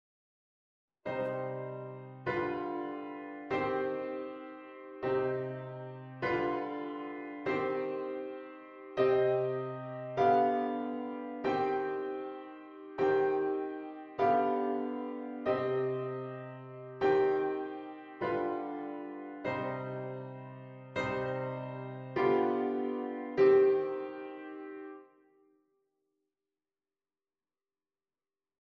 V4/3 als doorgangsakkoord tussen I en I6 (en: combinatie met 'sopraanfiguurtjes')
b. in mineur: